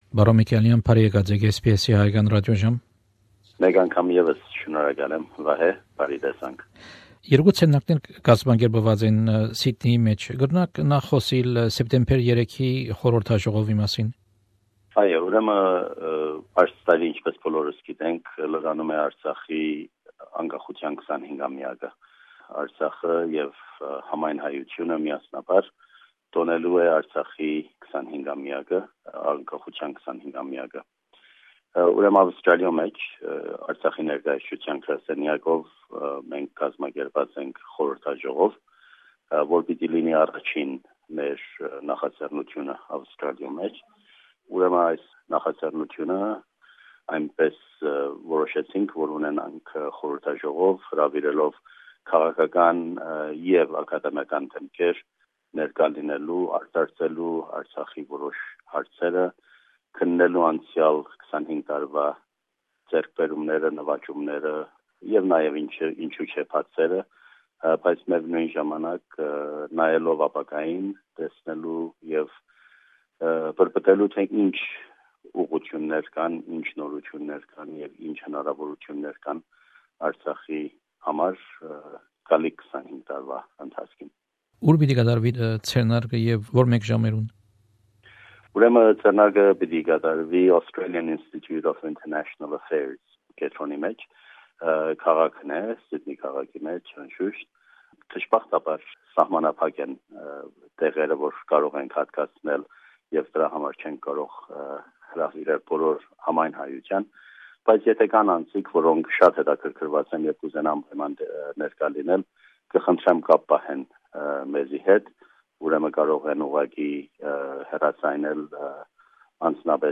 Հարցազրոյց Աւստրալիոյ մէջ ԼՂՀ Մշտական Ներկայացուցիչ՝ Պրն Քայլար Միքայէլեանի հետ Արցախի անկախութեան 25ամեակի տօնակատարութիւններու մասին: